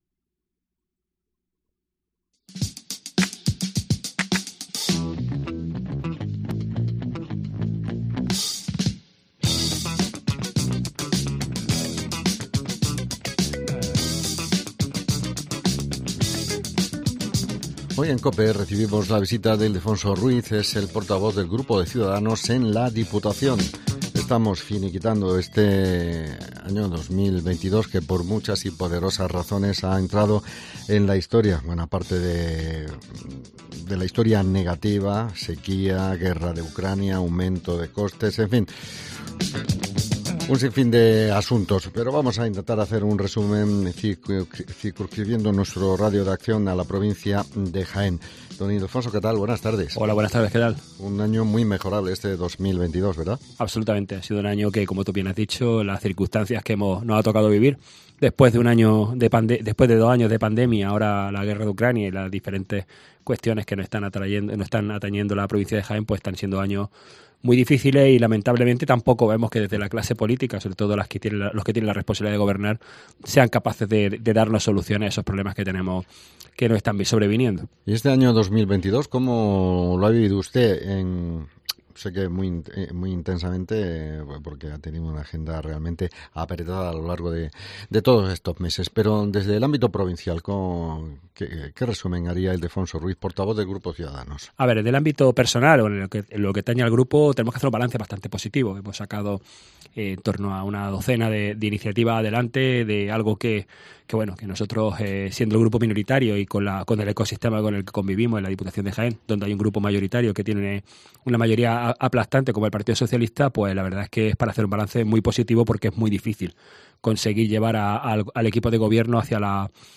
Hoy hablamos con Ildefonso Ruiz diputado provincial de Ciudadanos